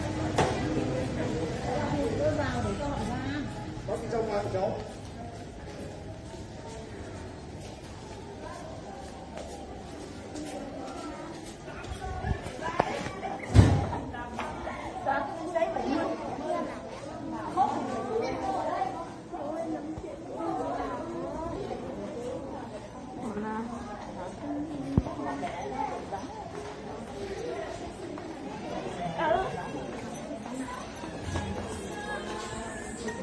Tiếng nói chuyện xì xào ở trong Bệnh viện, Phòng khám bệnh
Thể loại: Tiếng con người
Description: Tiếng nói chuyện xì xào, bàn tán của nhiều người ở trong Bệnh viện, Phòng khám bệnh.
tieng-noi-chuyen-xi-xao-o-trong-benh-vien-phong-kham-benh-www_tiengdong_com.mp3